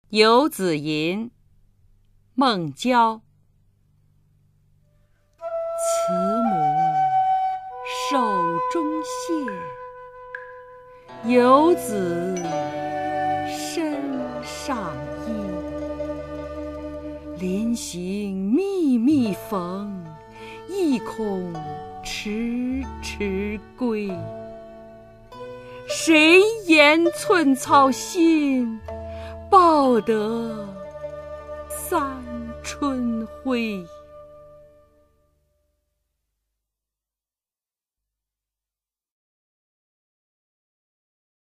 孟郊-游子吟 配乐诗朗诵
介绍： [隋唐诗词诵读]孟郊-游子吟 配乐诗朗诵